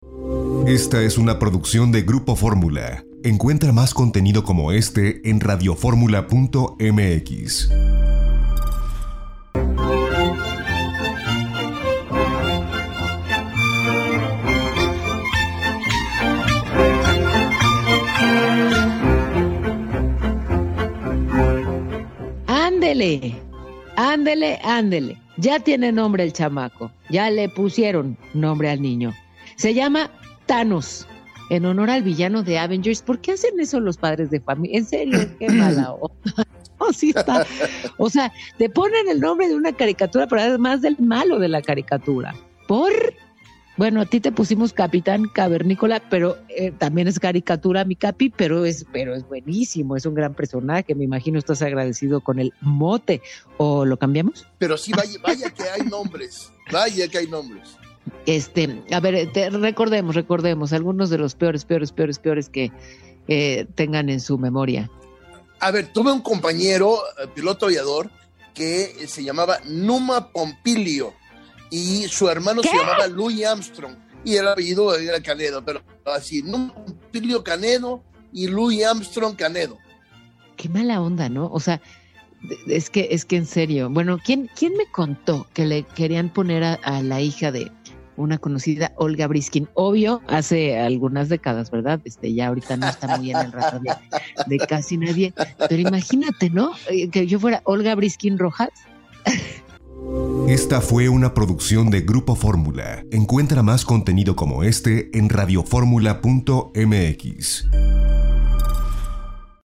Nota curiosa con Paola Rojas en Radio Fórmula: autoridades de Colombia capturaron a un hombre que fue sorprendido intentando traficar heroína con unos glúteos postizos.